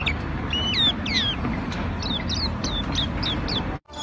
红胁绣眼鸟鸣声